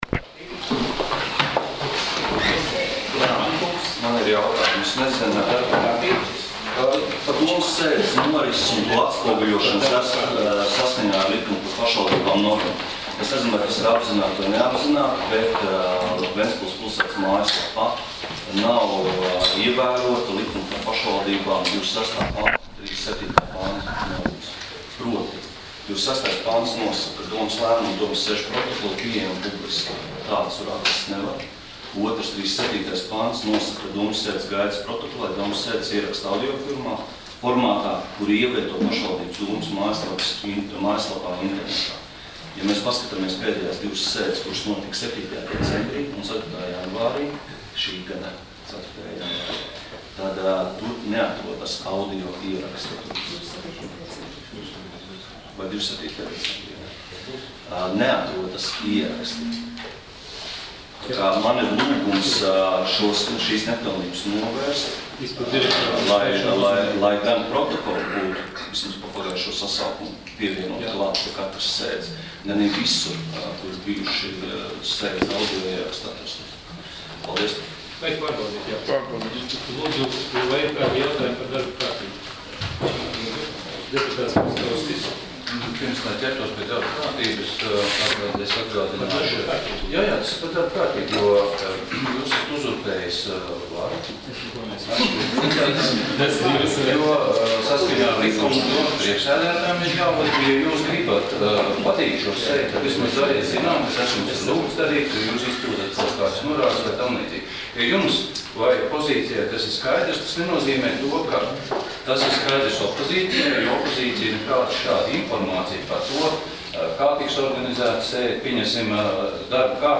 Domes sēdes 04.01.2018. audioieraksts